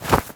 foley_object_throw_move_01.wav